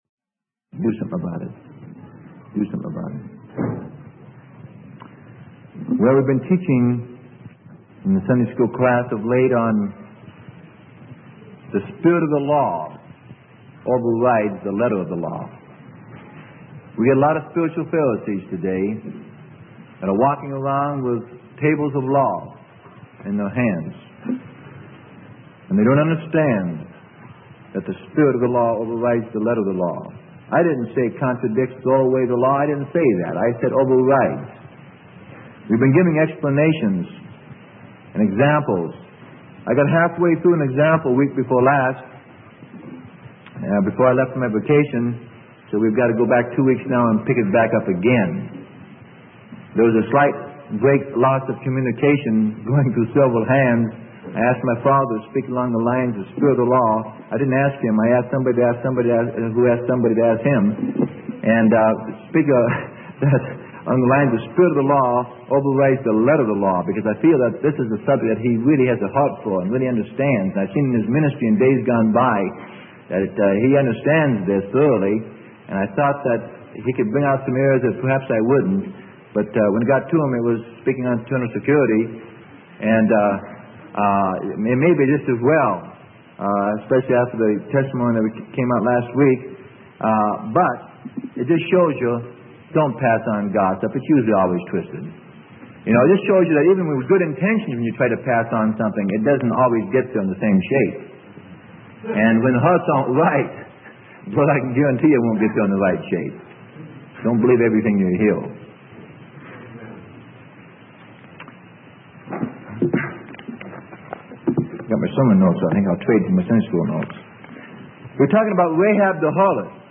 Sermon: Spirit vs Letter of the Law - Part 3 - Freely Given Online Library